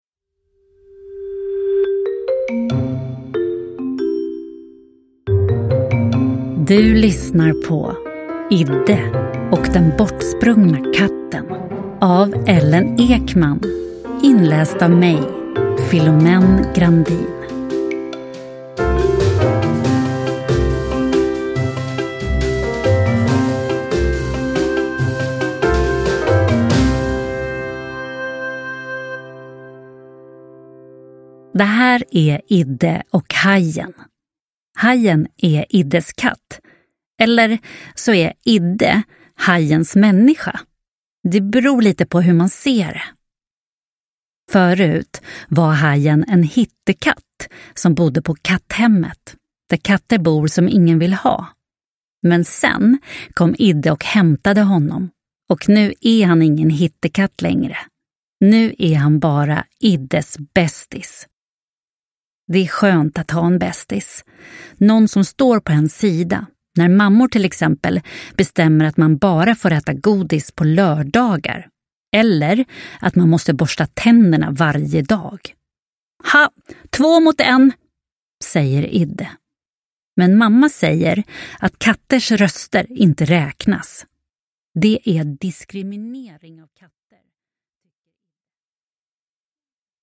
Idde och den bortsprungna katten – Ljudbok – Laddas ner